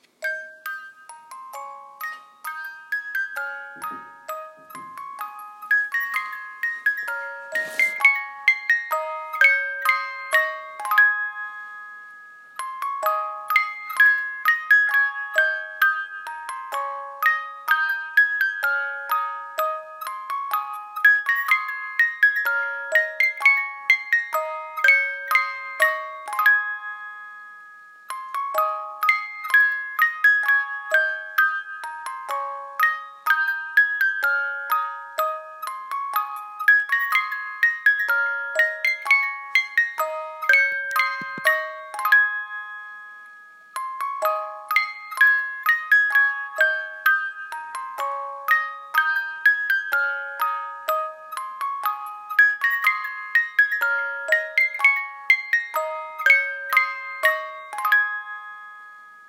Započúvajte sa do tónov hracej skrinky a razom sa ocitnete vo svojich detských rokoch.
Hracia skrinka v tomto prevedení slúži iba ako dekorácia s hracím strojčekom.